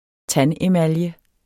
Udtale [ ˈtan- ]